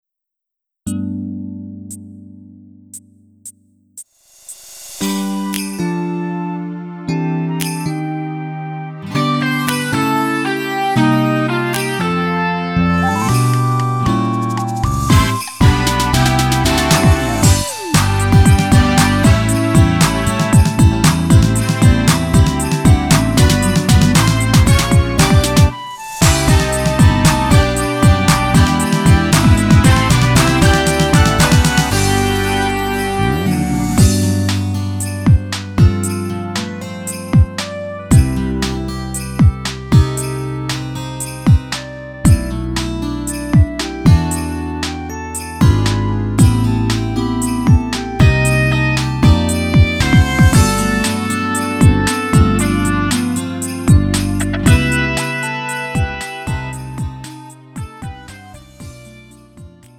음정 원키 3:13
장르 구분 Lite MR